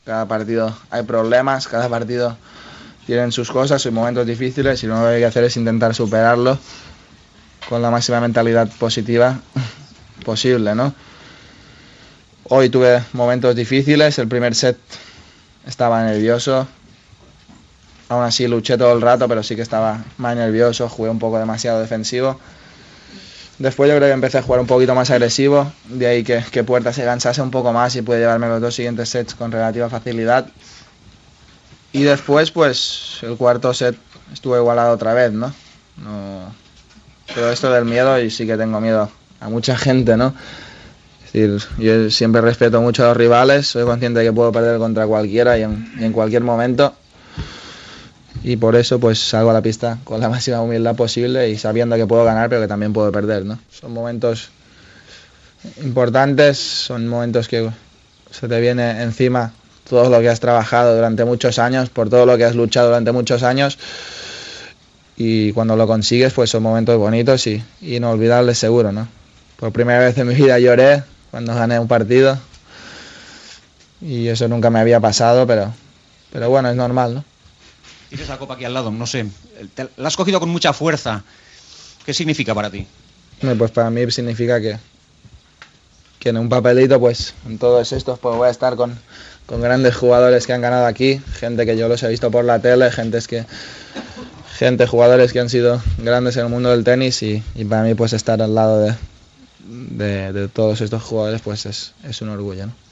Transmissió des de París de la final del torneig de tennis Roland Garros.
Declaracions de Rafael Nadal després del partit.